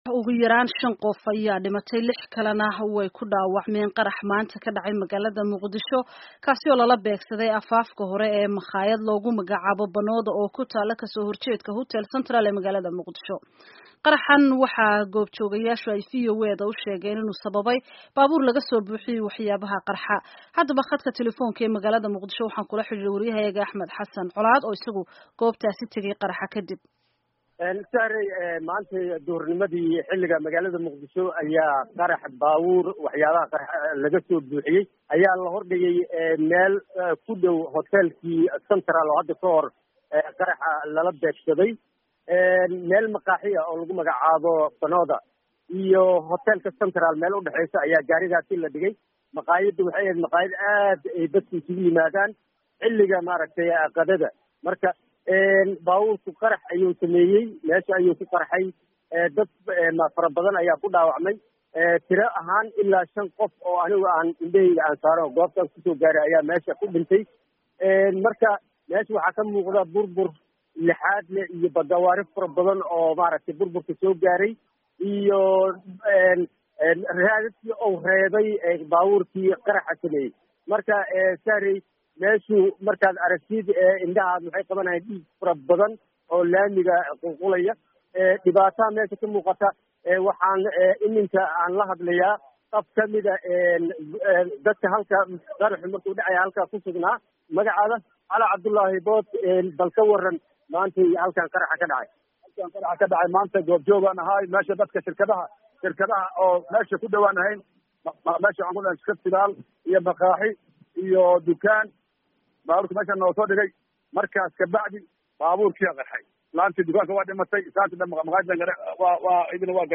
Dhegayso: Waraysiyo la Xidhiidha Qaraxa Muqdisho